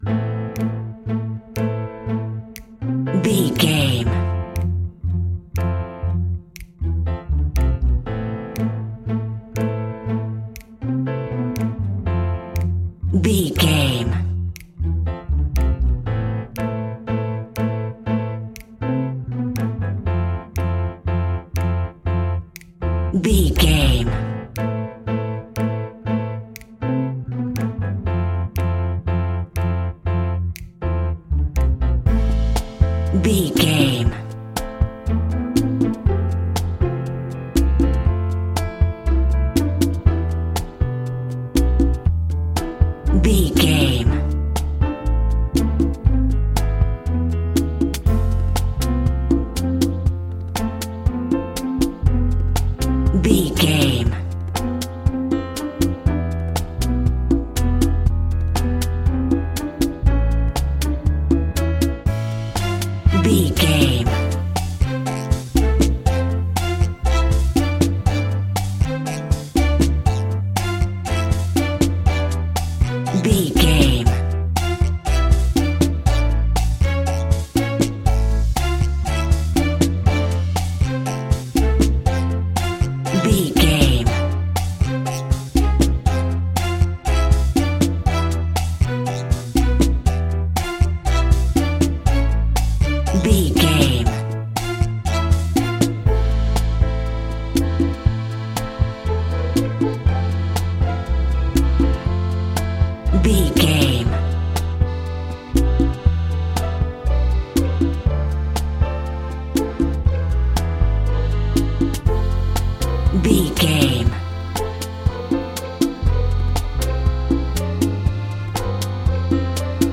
Aeolian/Minor
instrumentals
maracas
percussion spanish guitar
latin guitar